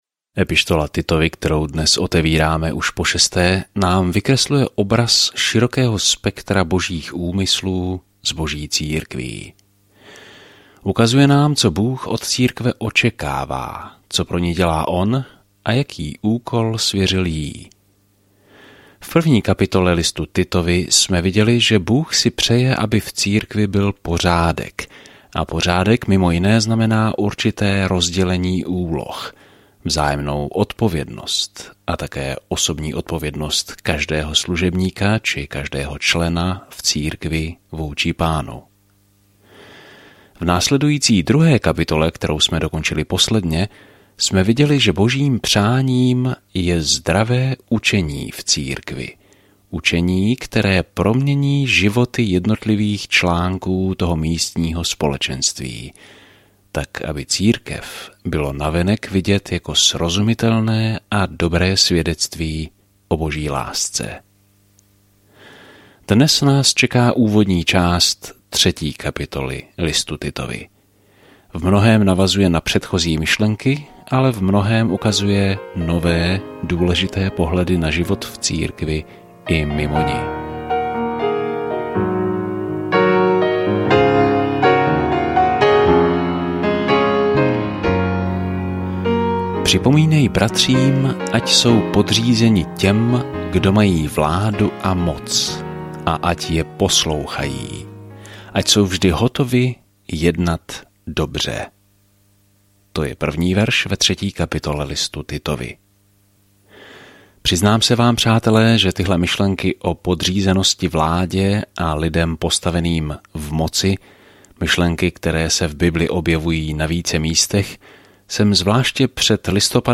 Písmo Titus 3:1-7 Den 5 Začít tento plán Den 7 O tomto plánu Tento dopis je určen mladému pastorovi a uvádí seznam protikulturních „kdo je-kdo v kostele“ a popisuje, jak si všechny typy lidí mohou navzájem sloužit a milovat se. Denně procházejte Titovi a poslouchejte audiostudii a čtěte vybrané verše z Božího slova.